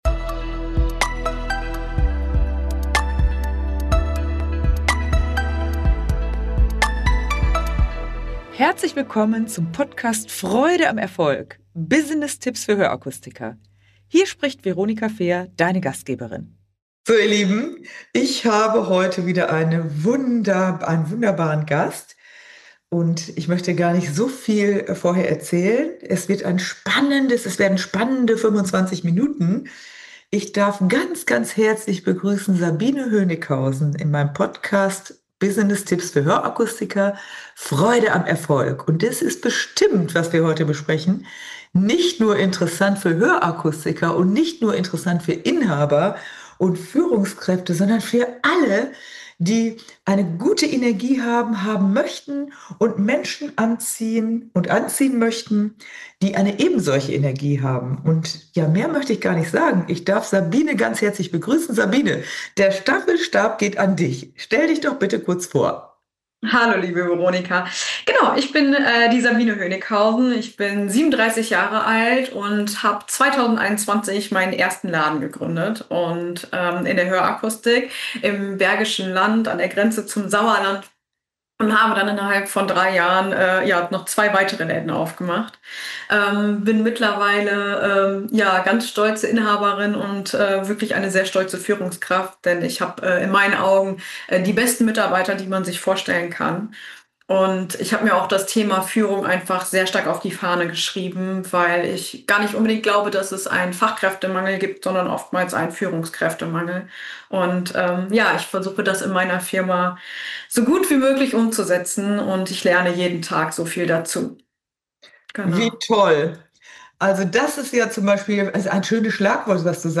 Zum Ende verrät sie ihre drei wichigsten Tipps. Ein hörenswertes Interview mit großem Mehrwert.